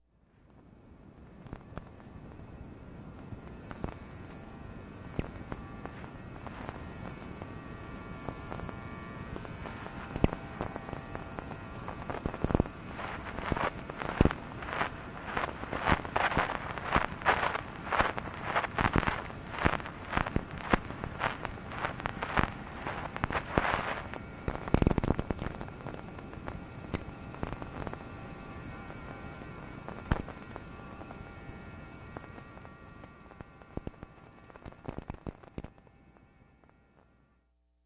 Phonography
"At extremely low frequencies, lightning crackles above me, the power grid buzzes around me,
and my footsteps shoot out sparks as they strike the Earth......."
Recorded using a McGreevy VLF/ELF receiver with antenna first pointed toward